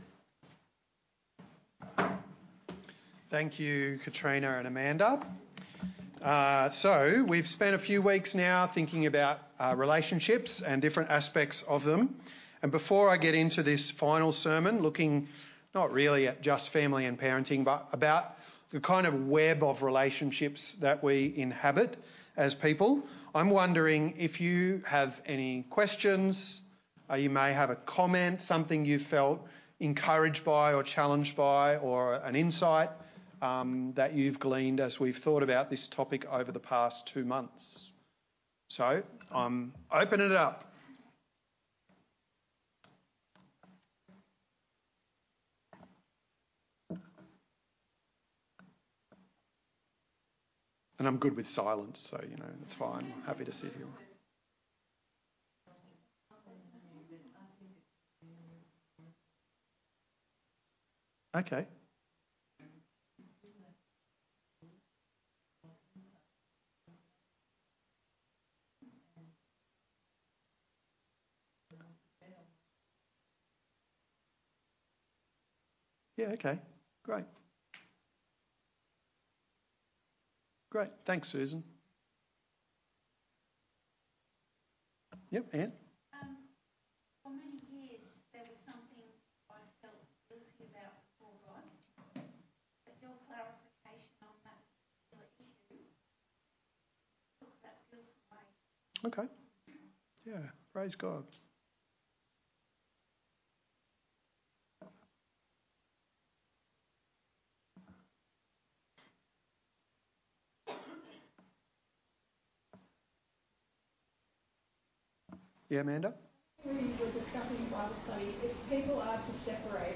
There is a Q&A session at the beginning of this recording. While it is difficult to hear the questions from the congregation, the focus of the question can be guessed from the helpful and thought provoking answers